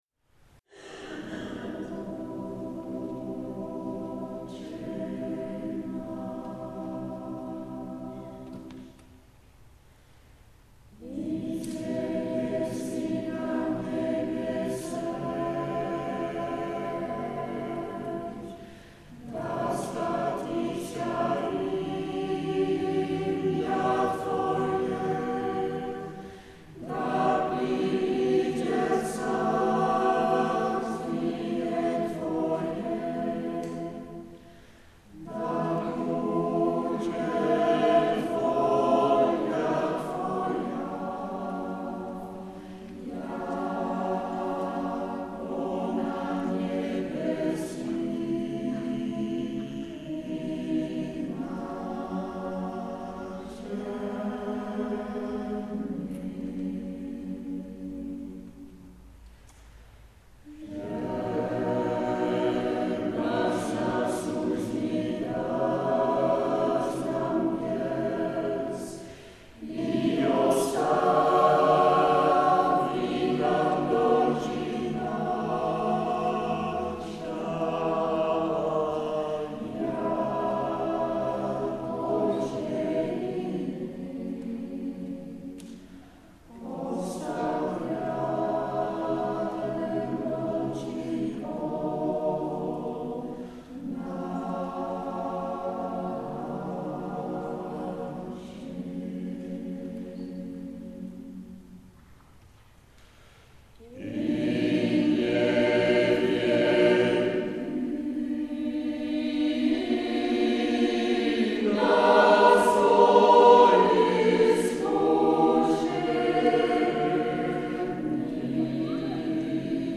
Aperitiefconcert 12 uur
Ze is eenvoudig, maar gekruid met harmonieën waar je koude rillingen van krijgt.